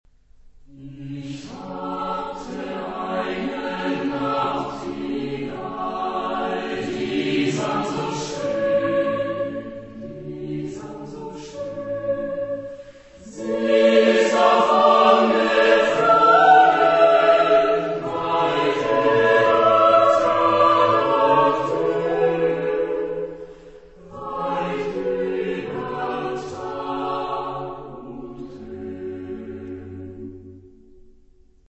in Weltliche Musik für Gemischten Chor a cappella
Genre-Style-Form: Secular ; Popular ; Romantic
Mood of the piece: slow
Type of Choir: SATB  (4 mixed voices )
Tonality: F sharp minor